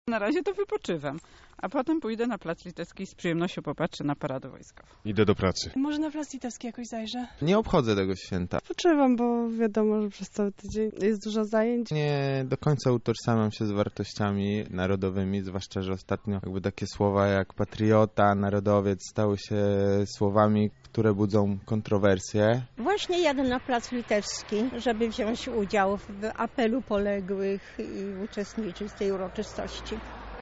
sonda – dzien niepodległości